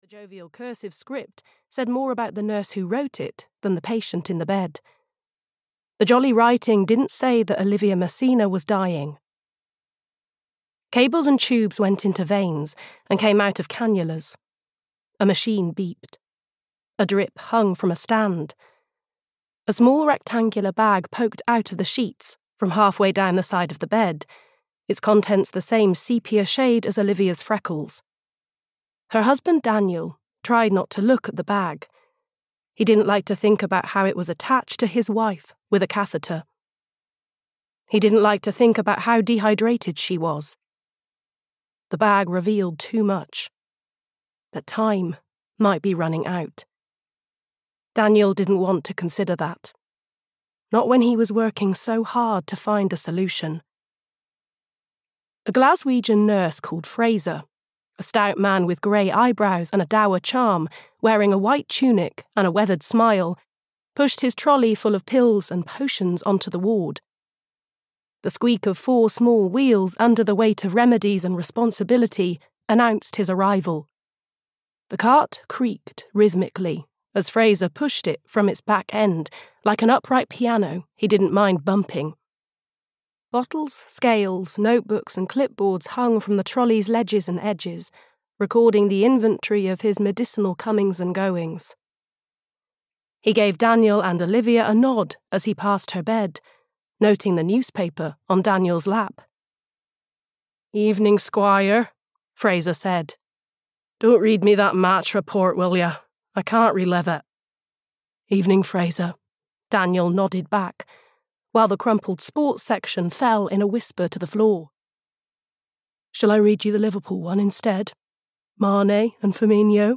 The Night We Met (EN) audiokniha
Ukázka z knihy